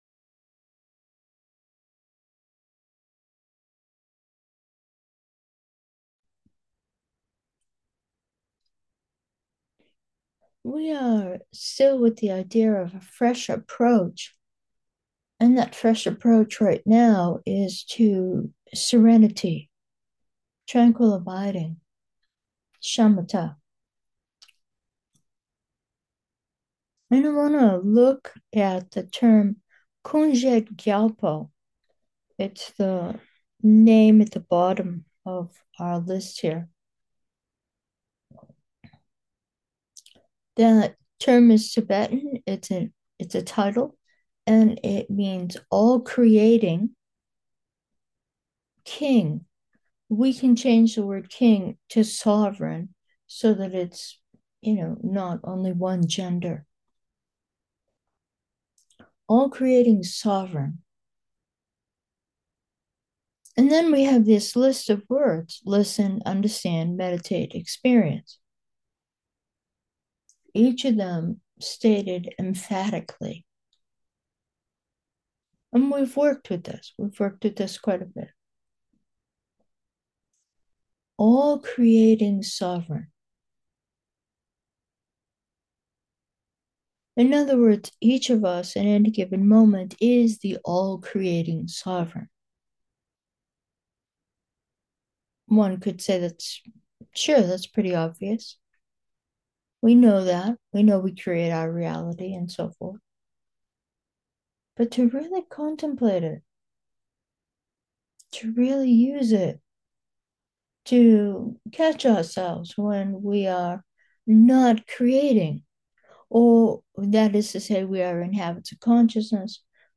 With this meditation session, we look at the meaning of the words Kunjed Gyalpo which translate as all creating king (the latter I re-term as sovereign). In other words, we are all creating, all the time.